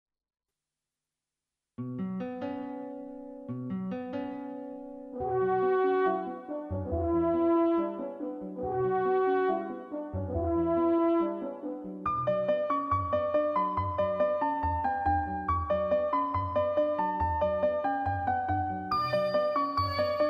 با صدای تکخوان کودک